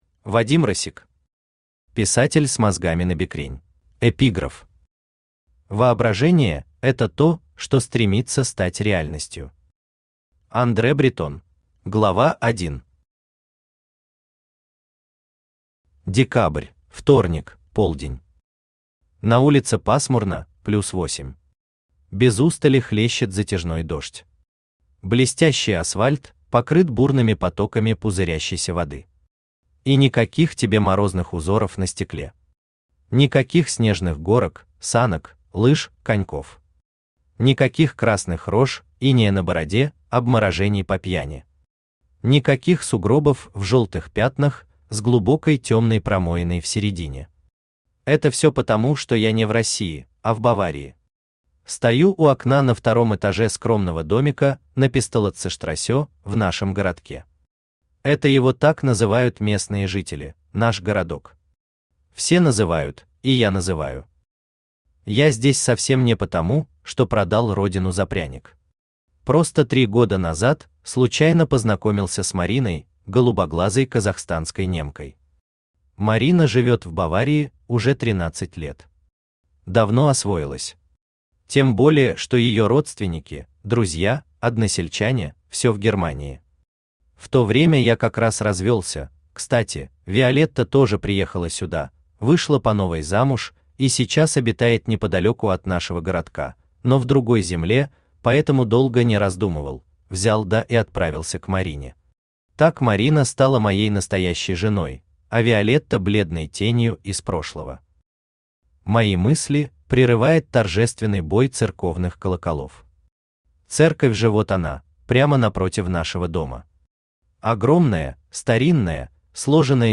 Аудиокнига Писатель с мозгами набекрень | Библиотека аудиокниг
Aудиокнига Писатель с мозгами набекрень Автор Вадим Россик Читает аудиокнигу Авточтец ЛитРес.